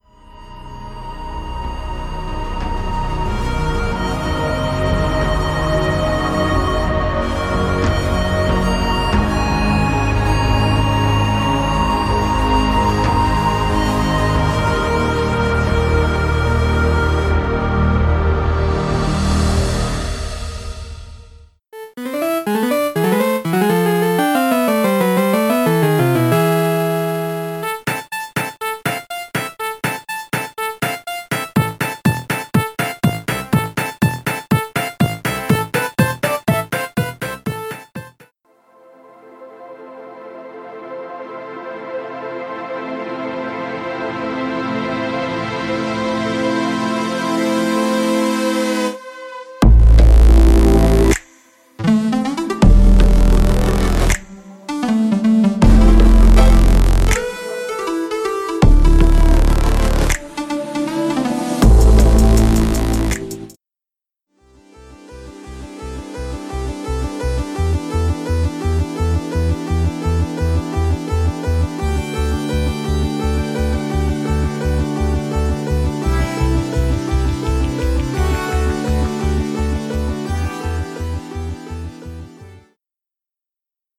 3. 合成器
我们将合成器明亮可识别的特征与闪闪发光的空间和直观、灵活的门音序器相结合。
它非常适合从强大，咆哮的低音线以及重现80年代霓虹灯垫的声音和8位音轨风格的一切。
您可以轻松更改音色以雕刻尖锐的打击乐声音或柔和，平滑上升的纹理。